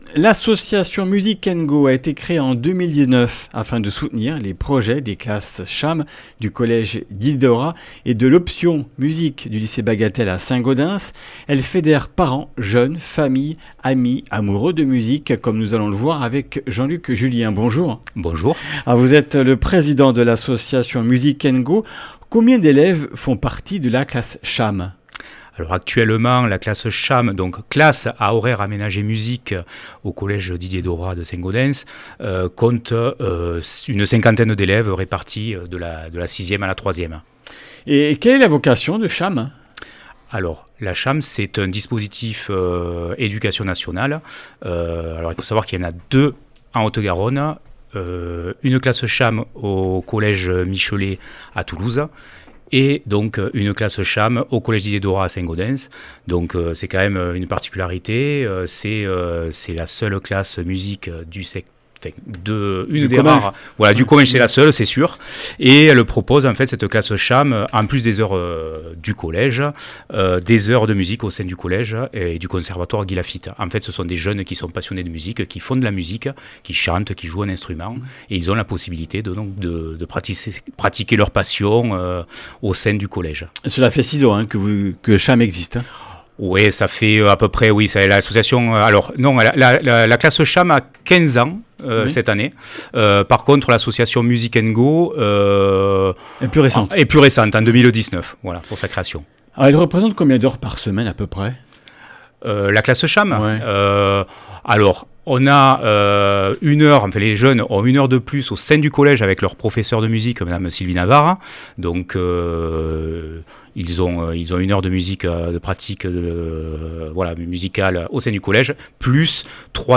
Comminges Interviews du 29 sept.
Une émission présentée par
Journaliste